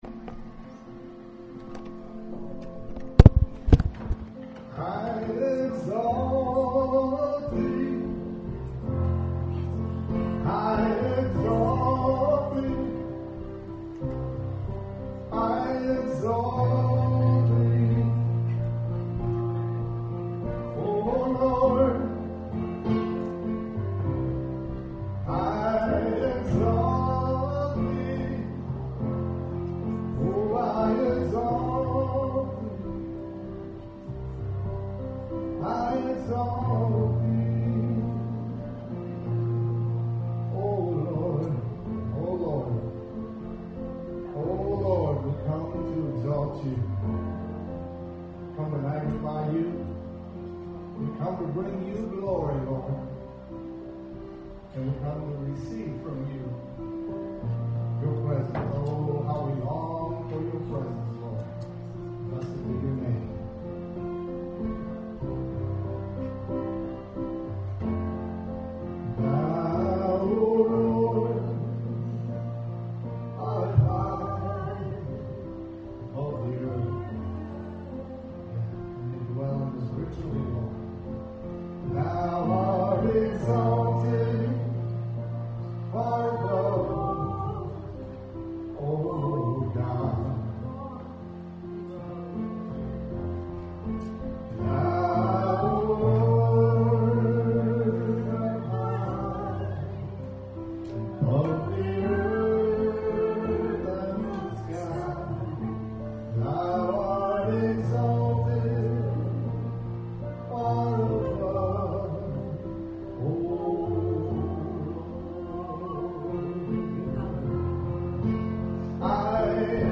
WORSHIP 928.mp3